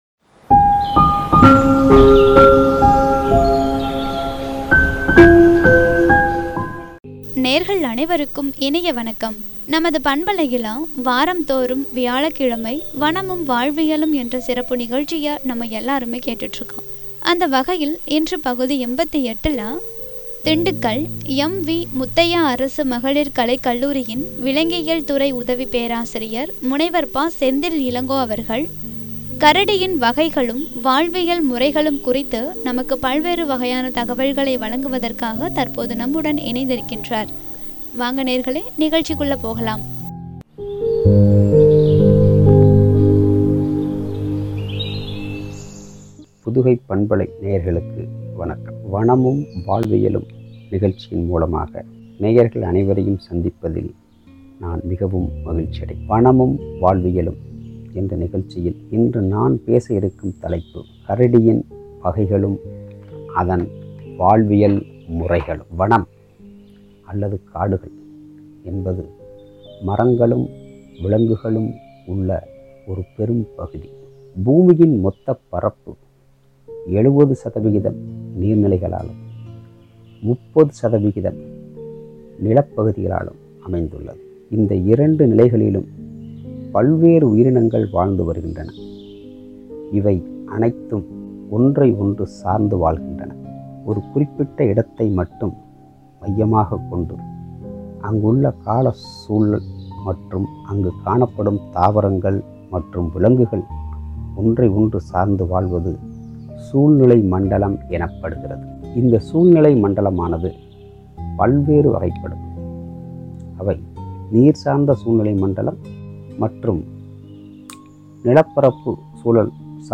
எனும் தலைப்பில் வழங்கிய உரையாடல்.